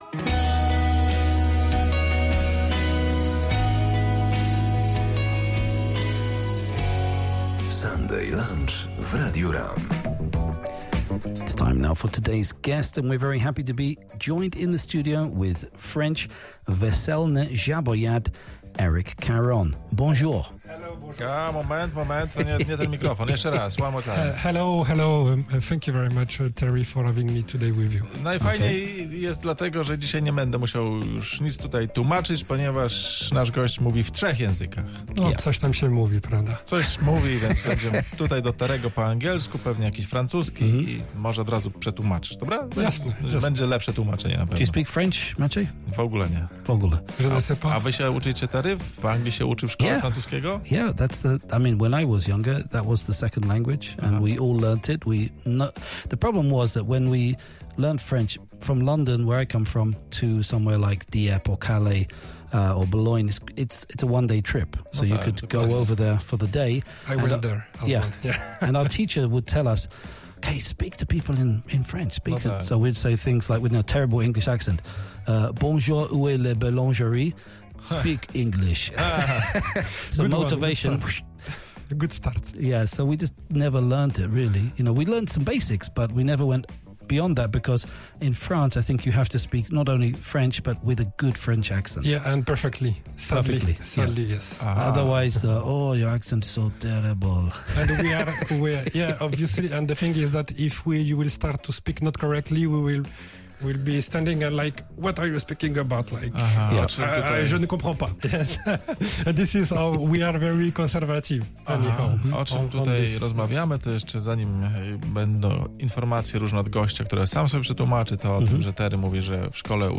Zapraszam do wysłuchania wywiadu Radia RAM, którego miałem przyjemność być gościem